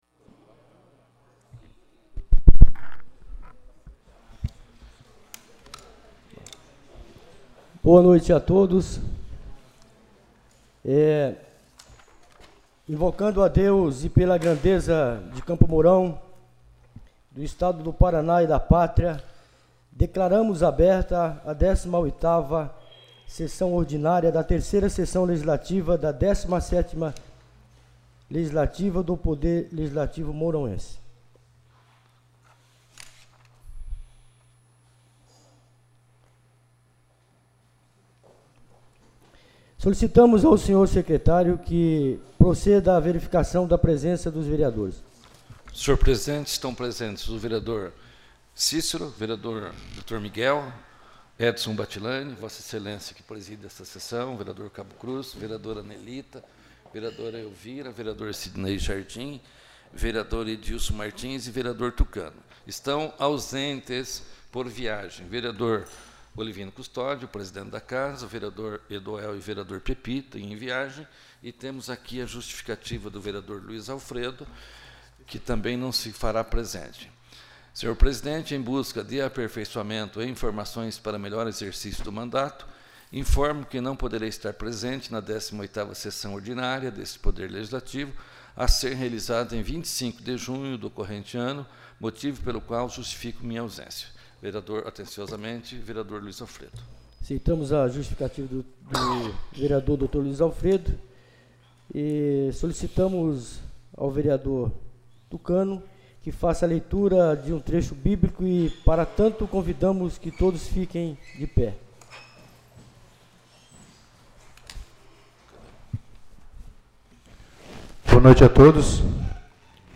18ª Sessão Ordinária